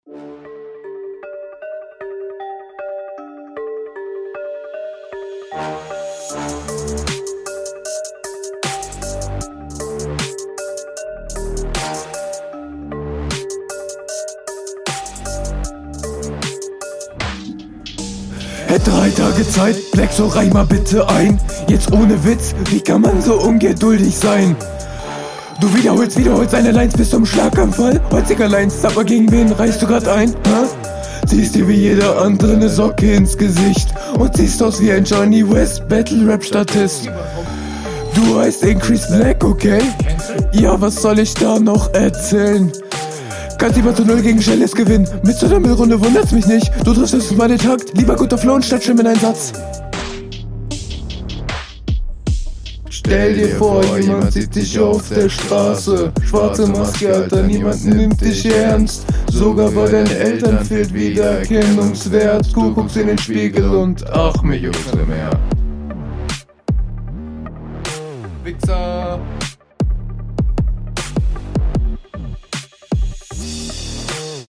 Cooler Beatpick